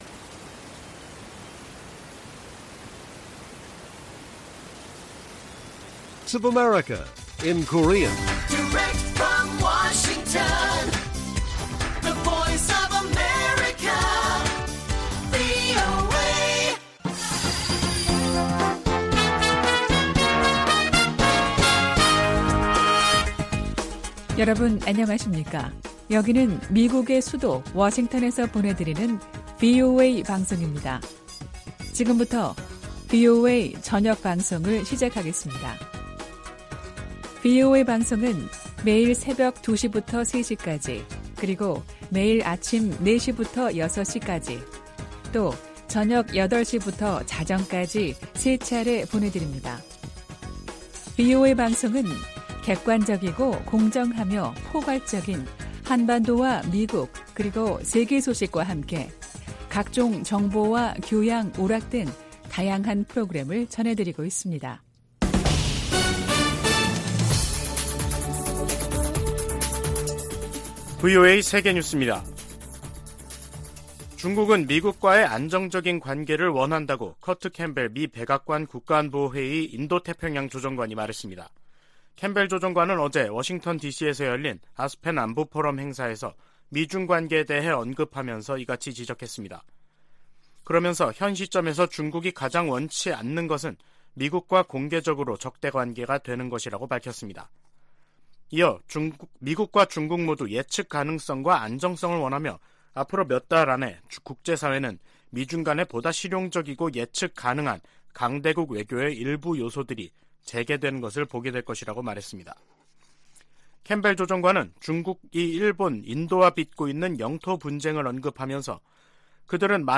VOA 한국어 간판 뉴스 프로그램 '뉴스 투데이', 2022년 12월 9일 1부 방송입니다. 7차 핵실험 가능성 등 북한 김씨 정권의 핵 위협이 미국의 확장억지와 핵우산에 도전을 제기하고 있다고 백악관 고위관리가 지적했습니다. 국무부 대북특별대표가 중국 북핵 수석대표와의 화상회담에서 대북 제재 이행의 중요성을 강조했습니다.